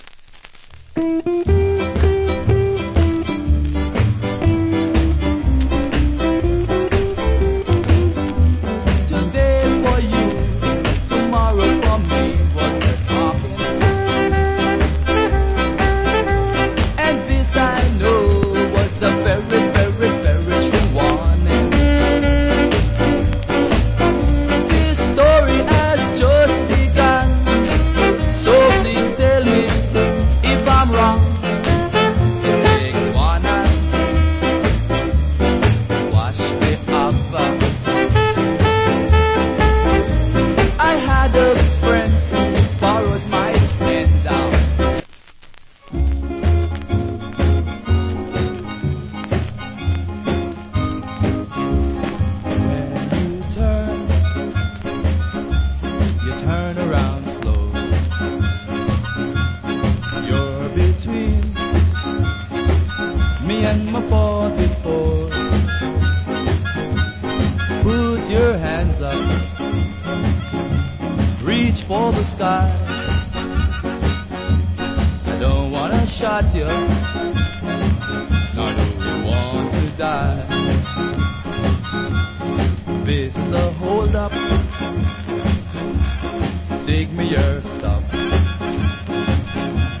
Condition VG(OK,HISS,LD,WOL)
Ska Vocal. / Good Ska Vocal.